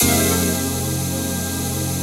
ATMOPAD24.wav